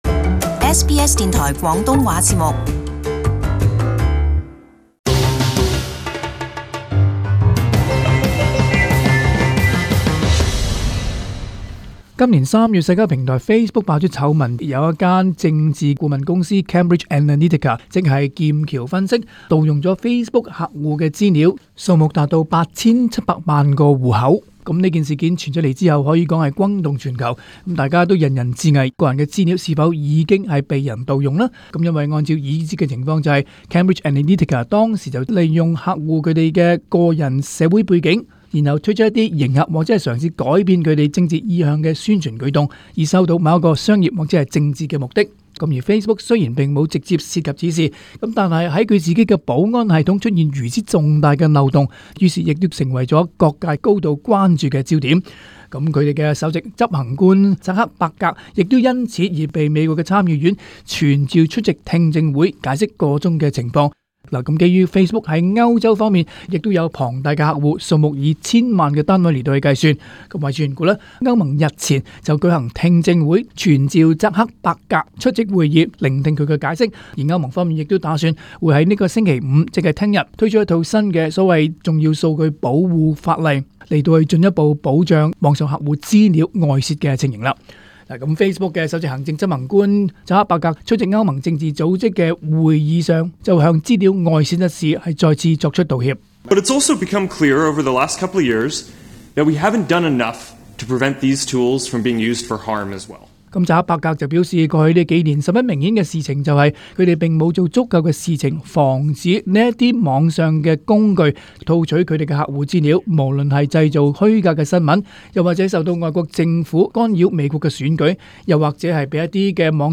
【時事報導】面書行政總裁朱克伯格接受歐盟公開聽證會